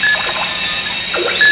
BackGround SE
backgroundSE(都市環境など)再生は非常に短縮された内容のLoopが多くなりがちですが、
▼　PCM Loopの場合　▼